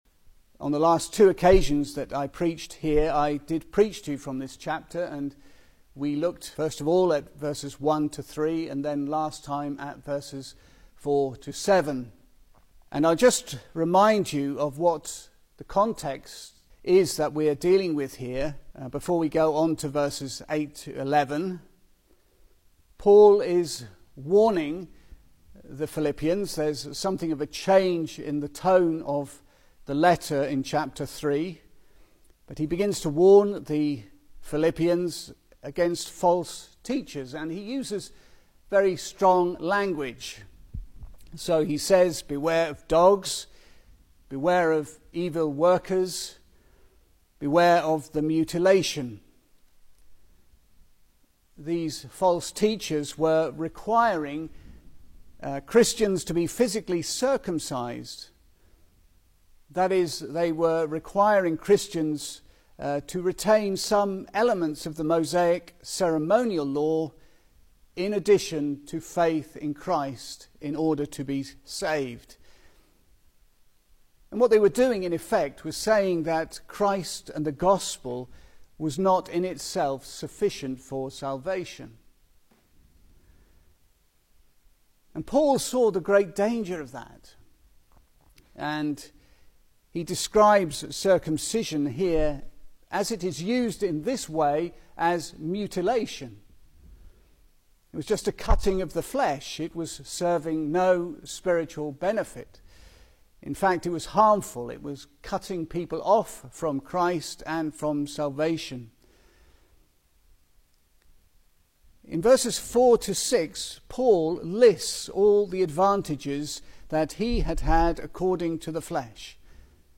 Service Type: Sunday Evening
Series: Single Sermons